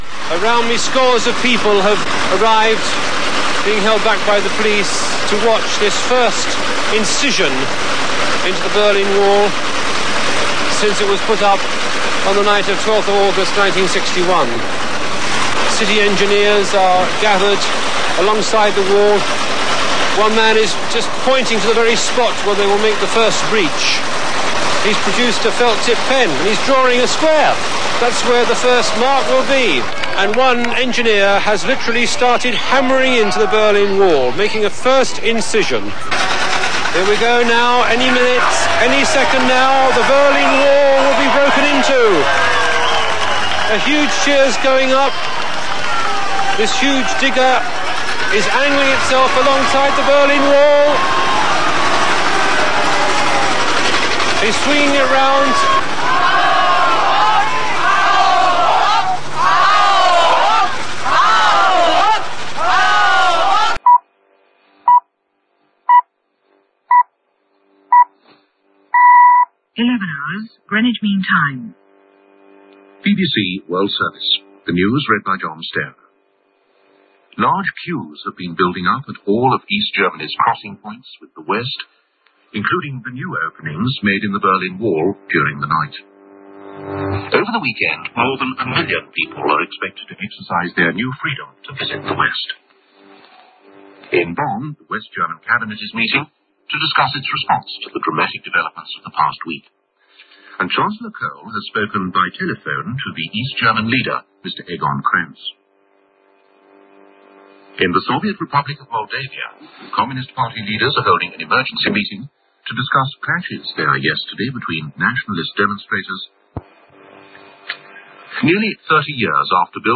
Various news reports concerning the Berlin Wall and its destruction.